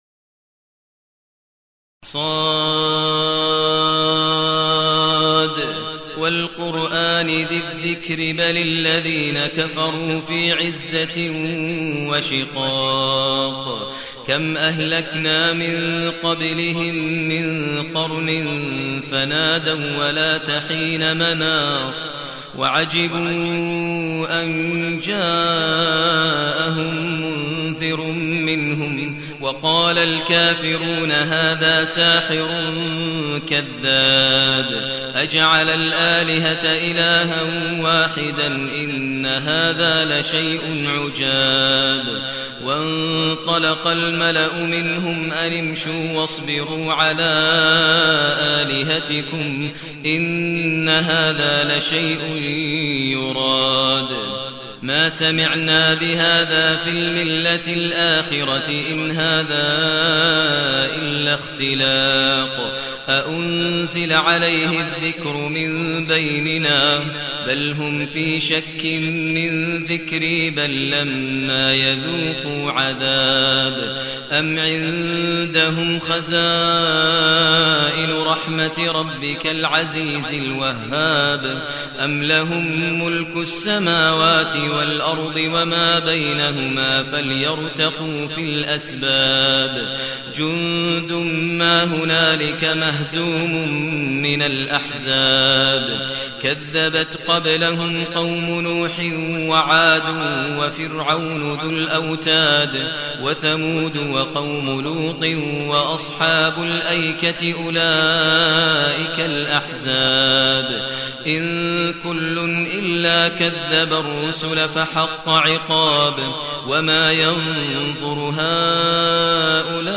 المكان: المسجد النبوي الشيخ: فضيلة الشيخ ماهر المعيقلي فضيلة الشيخ ماهر المعيقلي ص